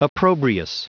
Prononciation du mot opprobrious en anglais (fichier audio)
Prononciation du mot : opprobrious